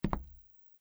MP3 · 17 KB · 立體聲 (2ch)